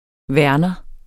Udtale [ ˈvæɐ̯nʌ ]